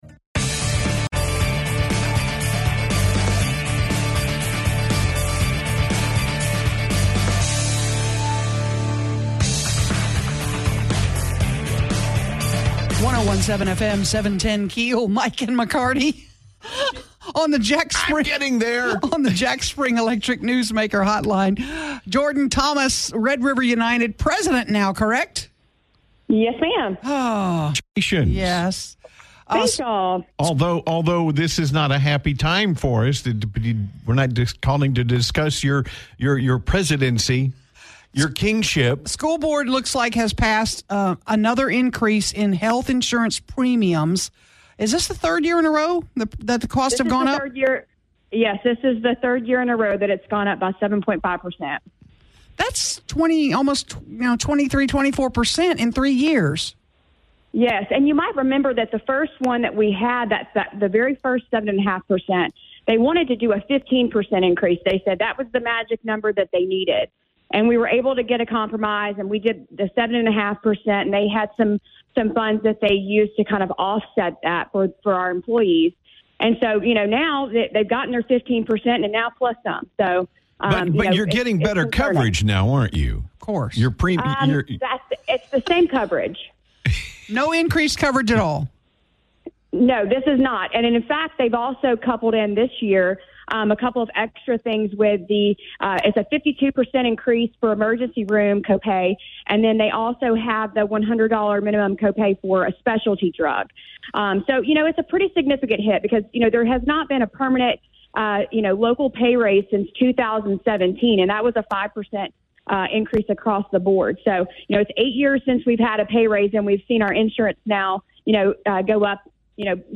including interviews with newsmakers along with their own personal takes on the day's hottest issues.
Be a guest on this podcast Language: en Genres: News Contact email: Get it Feed URL: Get it iTunes ID: Get it Get all podcast data Listen Now...